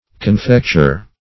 Confecture \Con*fec"ture\, n.